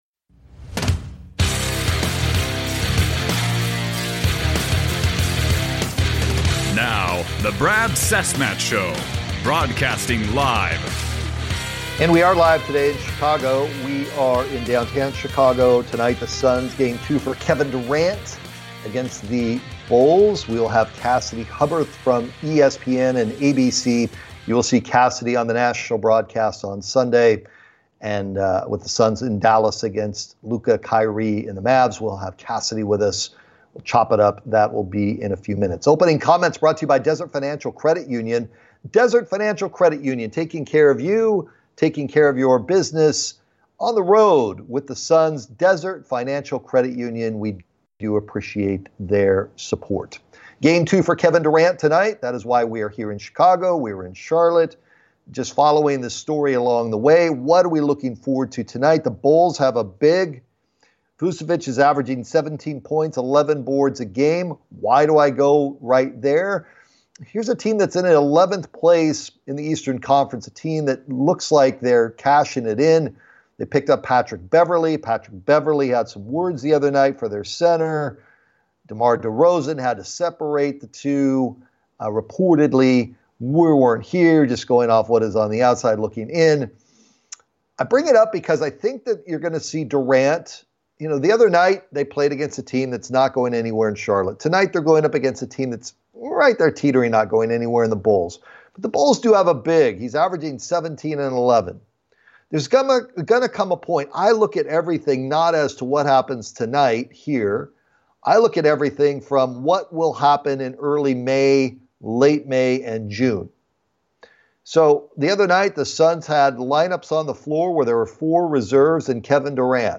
broadcasts live from Chicago